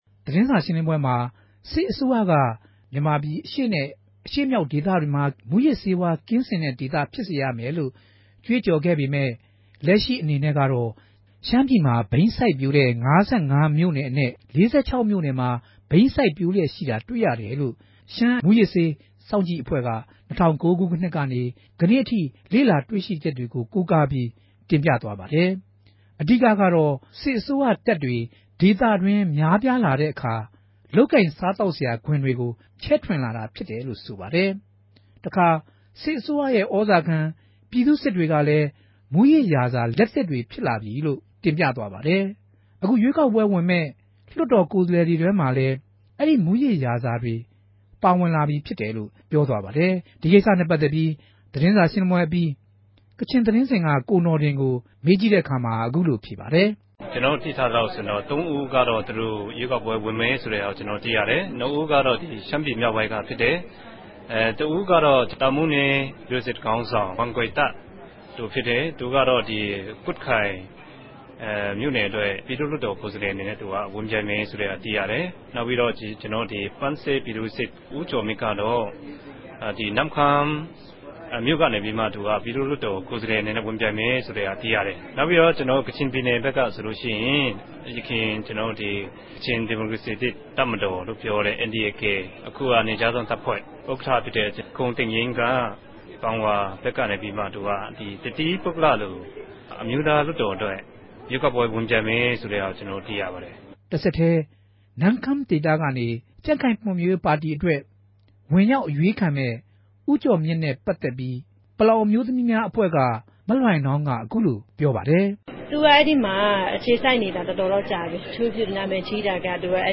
သတင်းတင်ပြးချက်။